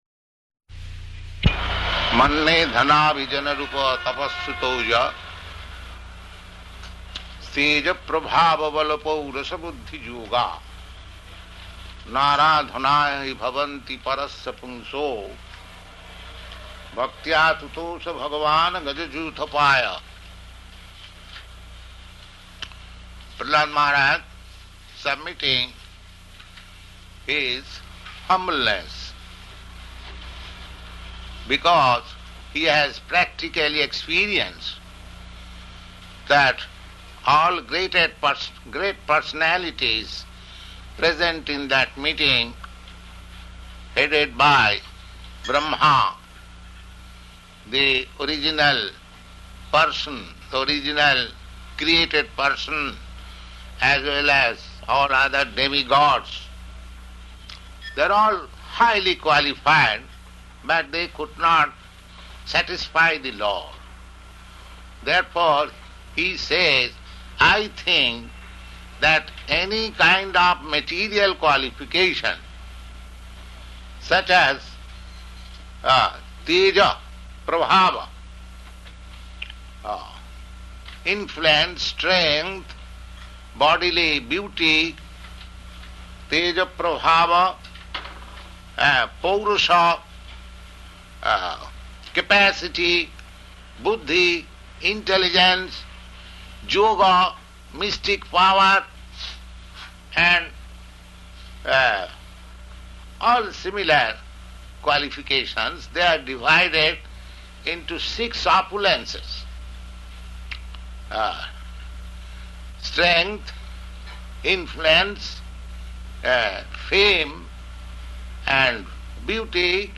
Location: Montreal